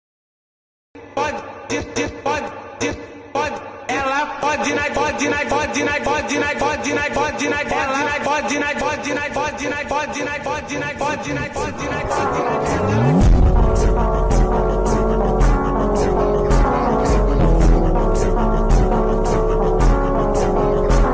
Alternative Ringtones